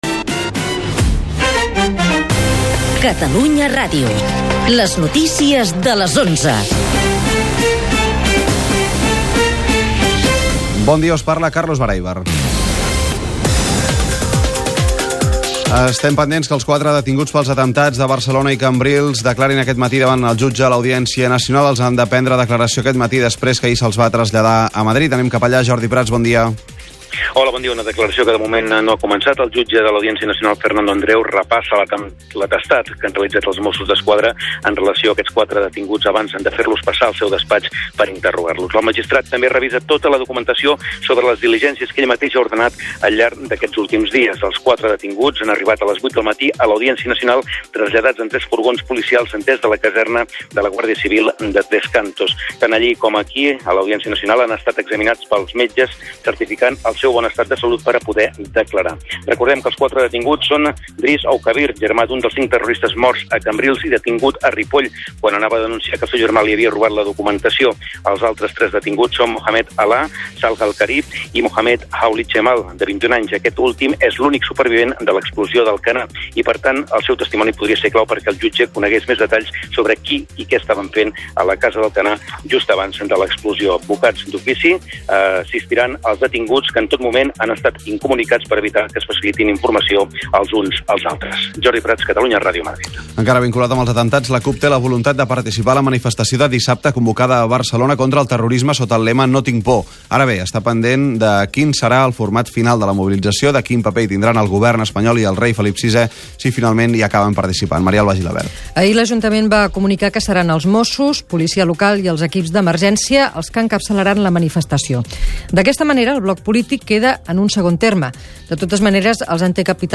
Emissora
Informatiu